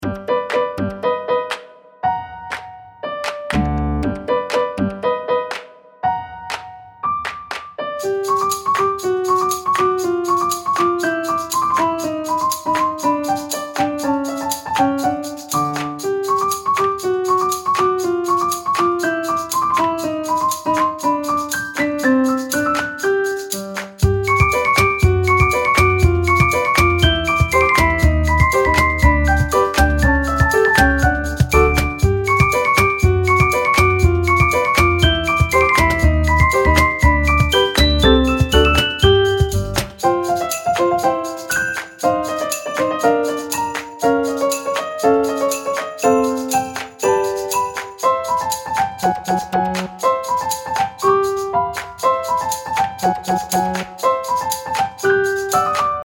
明るい楽曲
【イメージ】おでかけ、やんちゃな子ねこ など